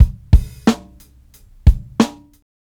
Smooth beat 91bpm.wav